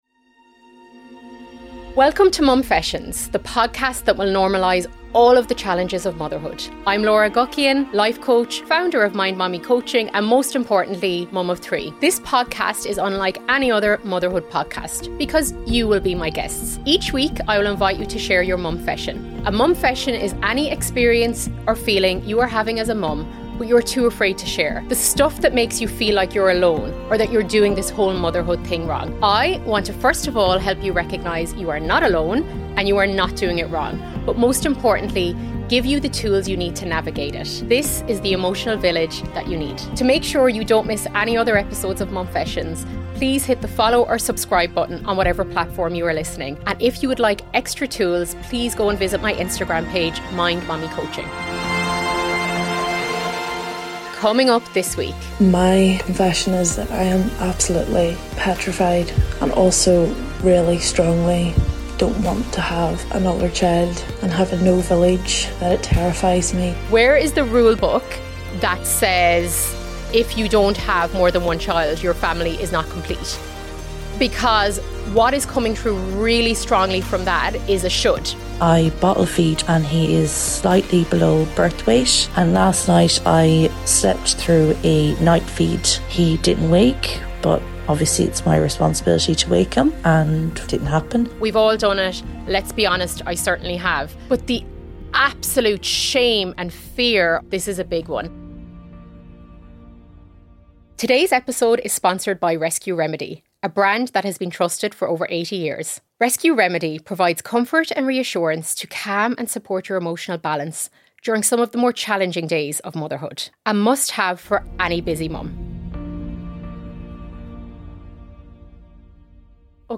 I am also joined by 2 x amazing Moms and talk about: 1. How to let guilt and shame go 2. How to decide if you want another baby JOIN THE VILLAGE: The Village is now closed.